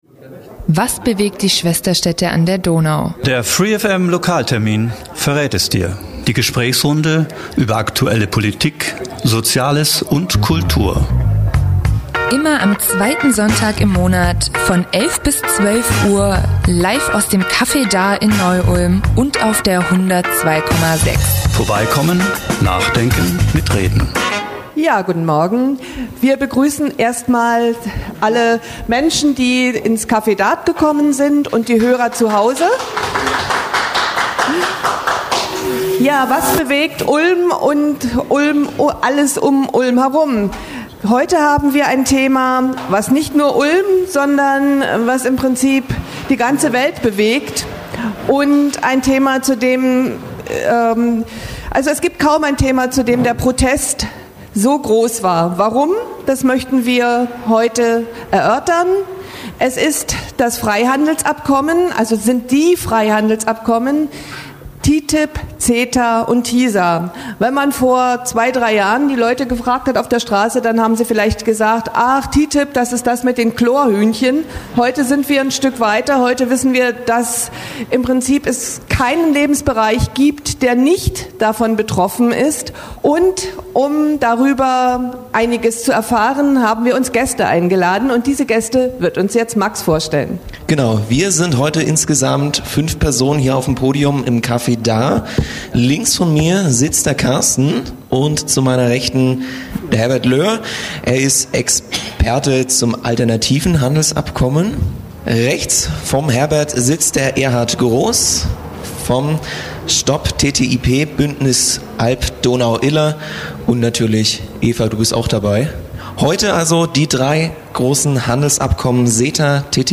Die Livesendung aus dem Neu-Ulmer Künstlercafé vom 10. Juli 2016 beschäftigt sich mit der aktuellen Debatte um Freihandelsabkommen.